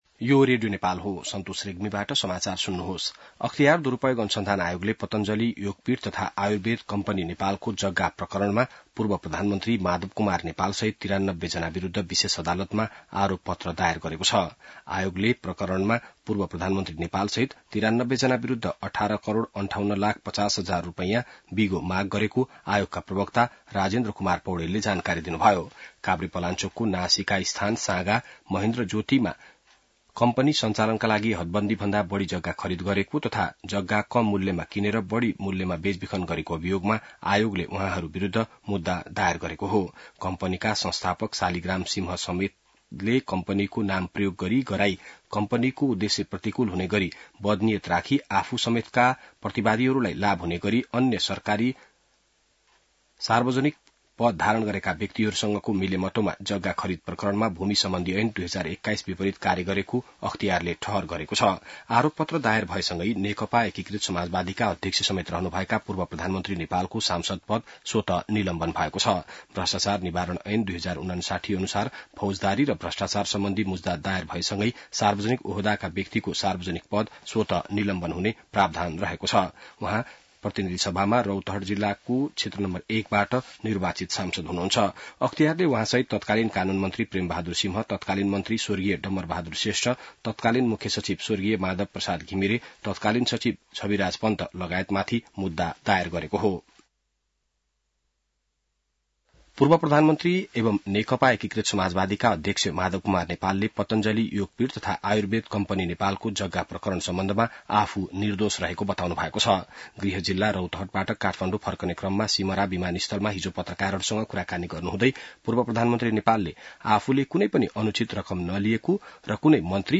An online outlet of Nepal's national radio broadcaster
बिहान ६ बजेको नेपाली समाचार : २३ जेठ , २०८२